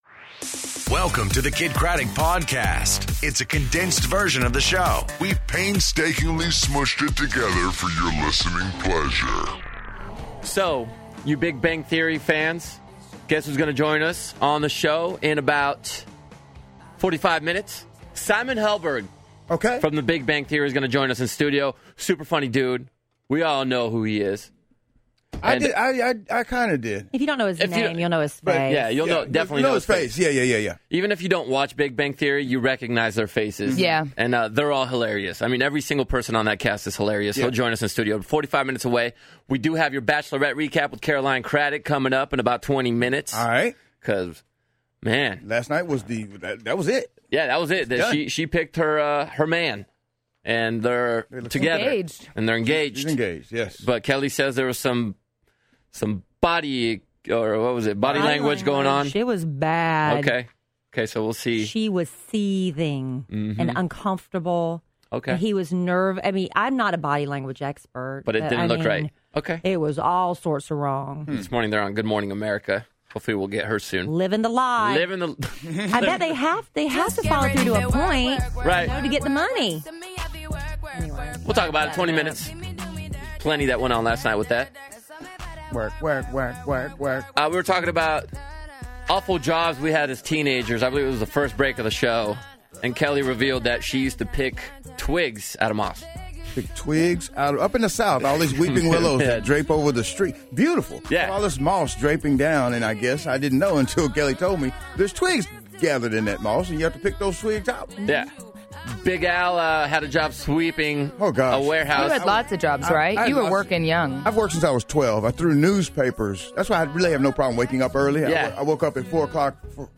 Worst Jobs, The Bachelorette Finale, And Simon Helberg In Studio